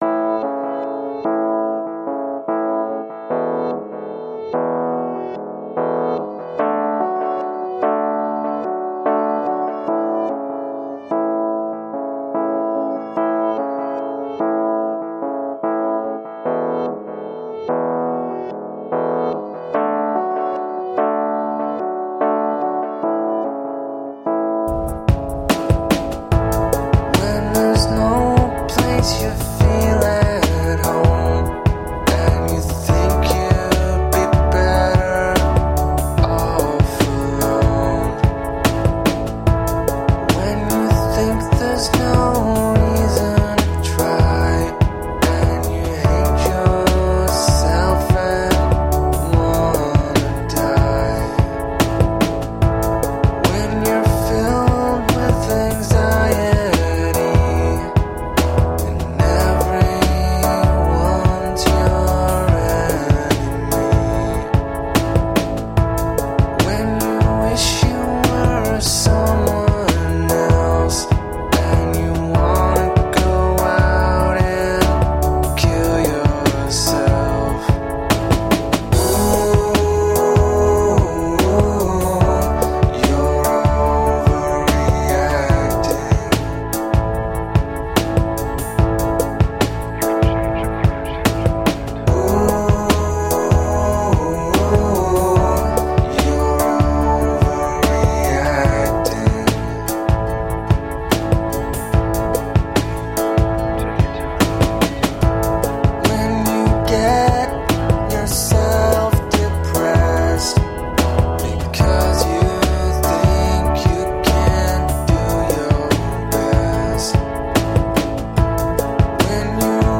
Brilliantly sardonic indie rock.